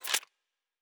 Weapon 03 Foley 3.wav